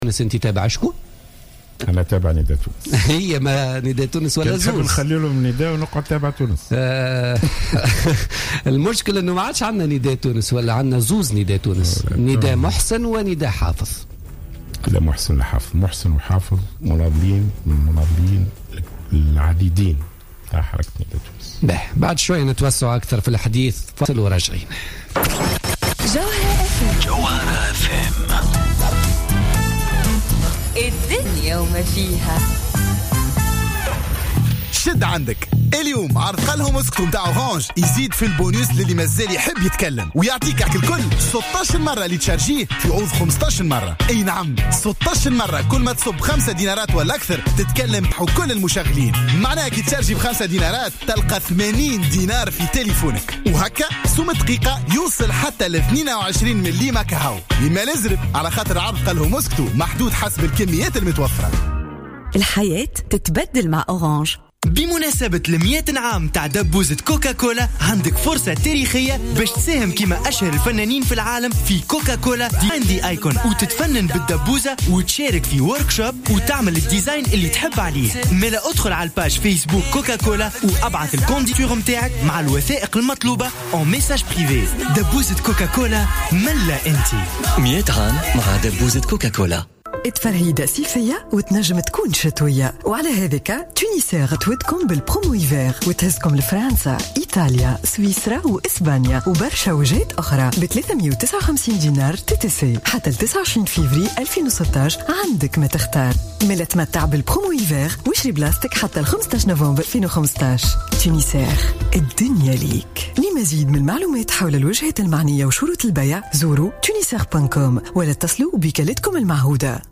أكد القيادي عن حركة نداء تونس خميس قسيلة ضيف بوليتيكا اليوم الثلاثاء 3 نوفمبر 2015 أن الحزب الأول في تونس يعاني أزمة حقيقية في قيادته مضيفا أن قيادات حزب نداء تونس لم تنجح بعد فوزه بأكبر كتلة نيابية في البرلمان من توجيهه الى المسار الصحيح خاصة بعد الفراغ الذي خلفه توجه مؤسسه الى منصب رئاسة الجمهورية.